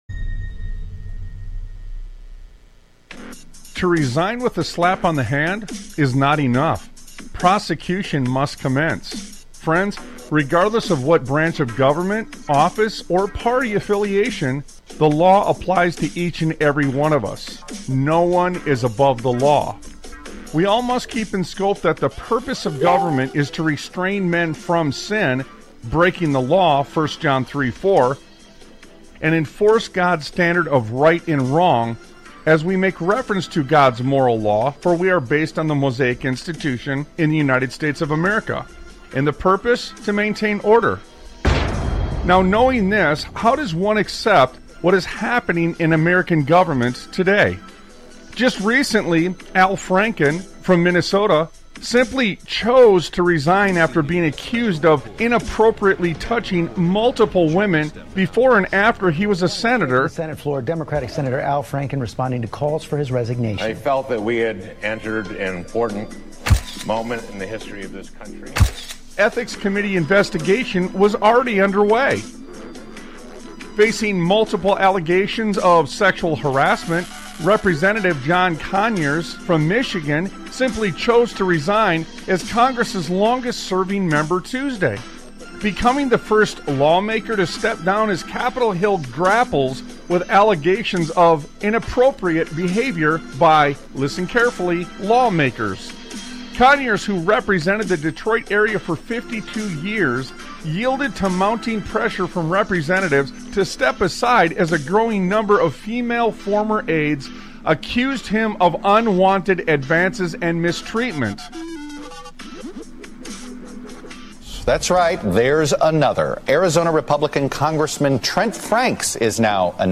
Sons of Liberty Radio